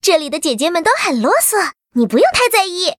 文件 文件历史 文件用途 全域文件用途 Fifi_amb_04.ogg （Ogg Vorbis声音文件，长度3.0秒，102 kbps，文件大小：37 KB） 源地址:游戏语音 文件历史 点击某个日期/时间查看对应时刻的文件。